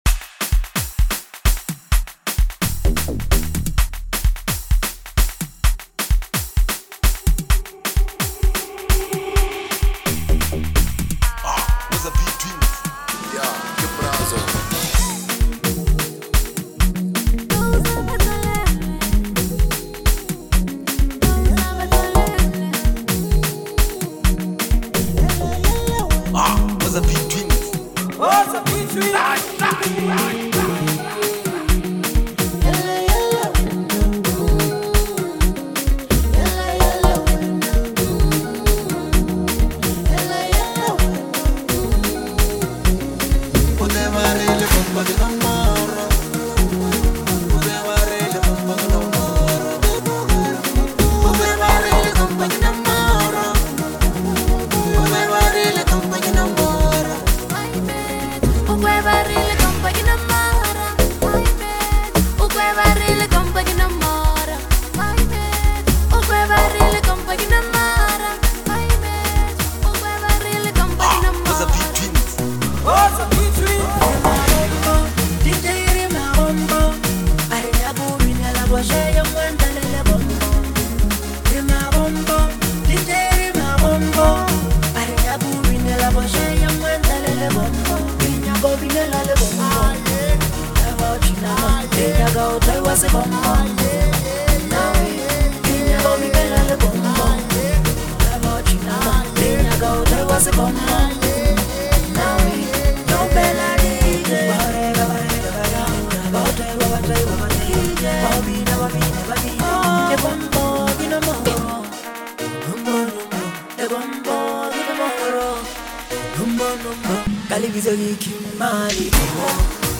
Turn up the volume and experience this electrifying banger!